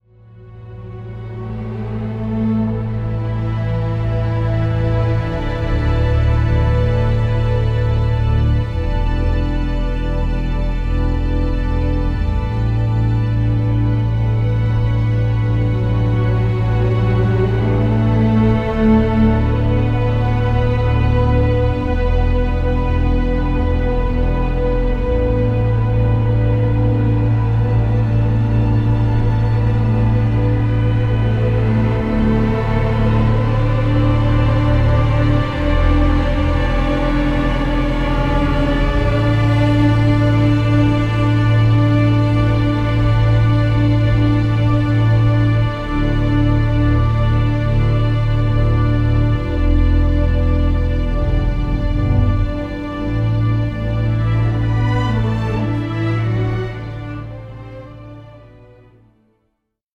ホーム ｜ JAZZ
ハープとモジュラー・シンセが奏でる瞑想的なサウンド